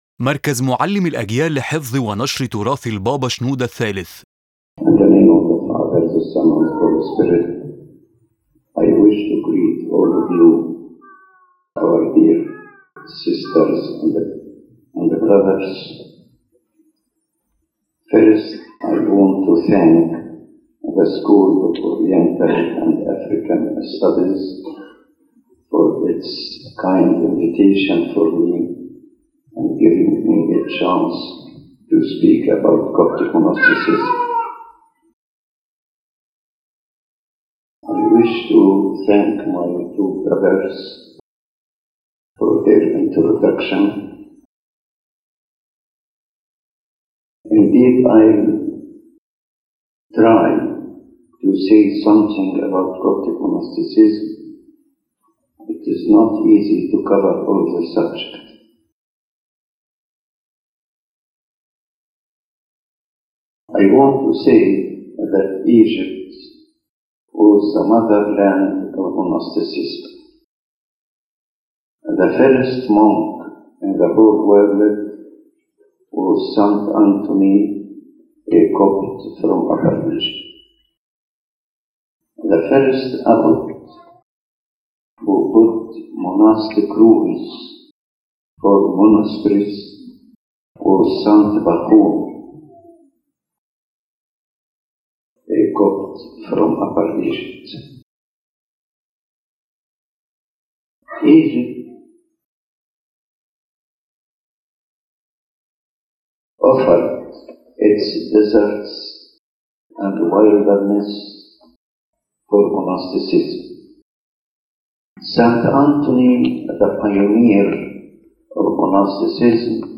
His Holiness began by thanking the School of Oriental and African Studies for the invitation and mentioned that his topic is Coptic monasticism, a vast subject that cannot be covered fully in a single lecture.